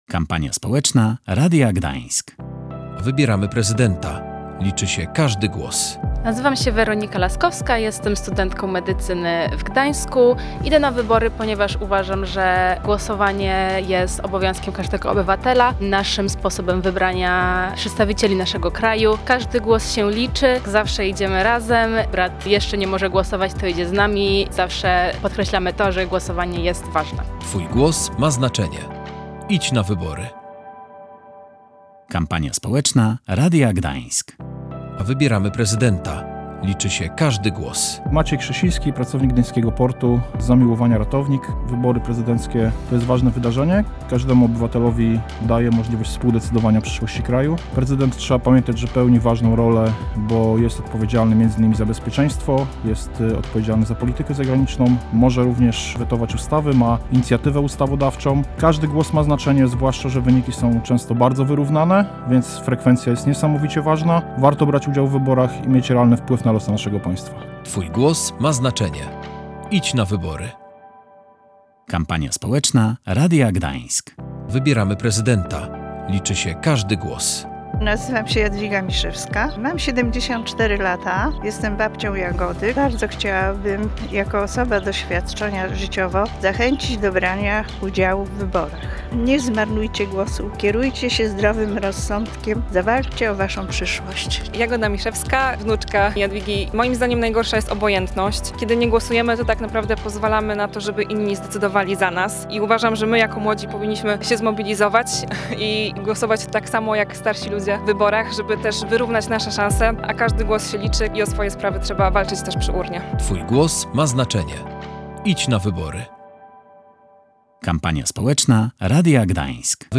Przygotowaliśmy profrekwencyjną kampanię społeczną, w której mieszkańcy Pomorza mówią, dlaczego warto zagłosować